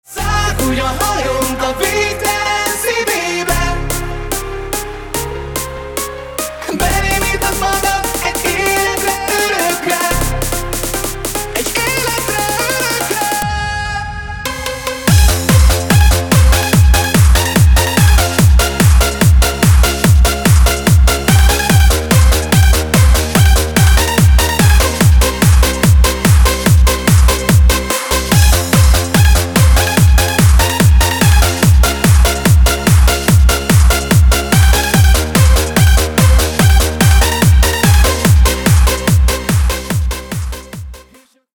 Hands Up remix